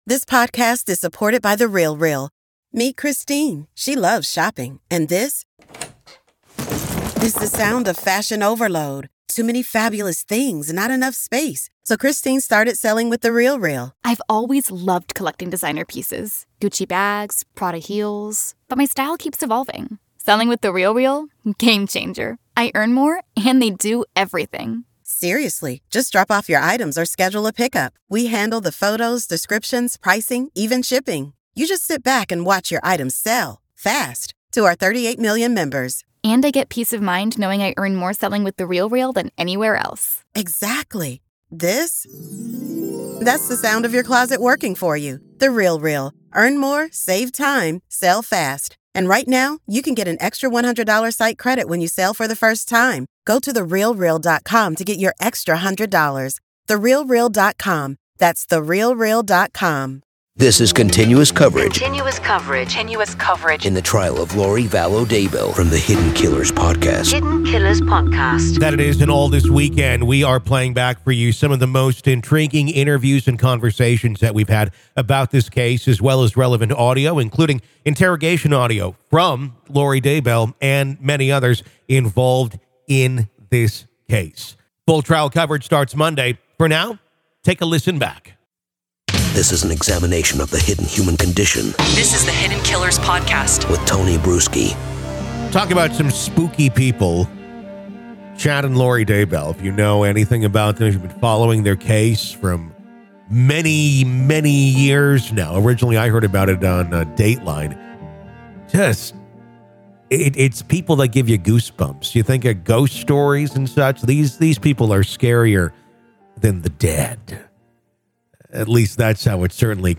Join us this weekend on our riveting podcast as we journey through the most captivating interviews and enthralling audio snippets that delve into the enigmatic case against Lori Vallow Daybell.